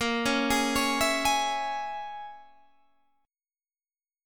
Bbm7b5 chord